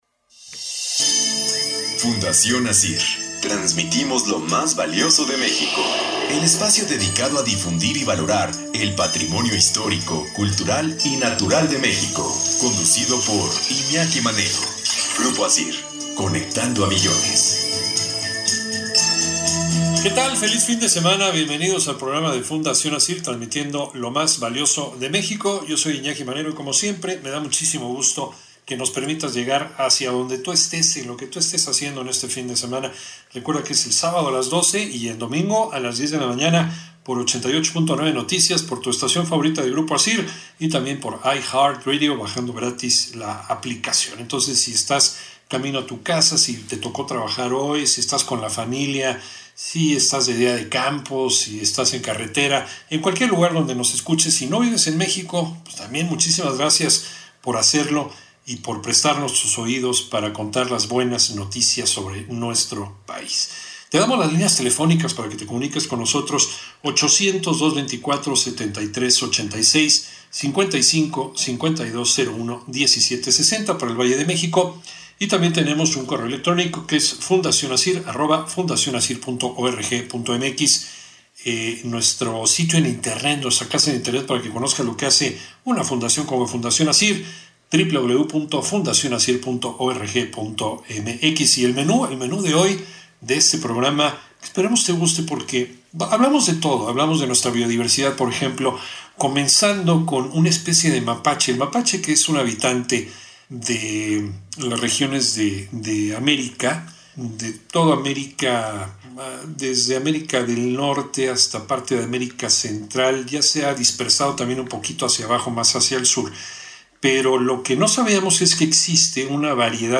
Entrevista
TEMA: Mapache enano de Cozumel Programa de radio de Fundación Acir El programa de Fundación Acir es conducido por Iñaki Manero, es un espacio que busca preservar y difundir los valores, la cultura y el patrimonio histórico de nuestro país, se transmite sábado y domingo, a las 12:00 y 10:00 hrs por 88.9 de FM en la Ciudad de México y área metropolitana, además de estar disponible en versión digital en I Heart Radio para todo México y E.U.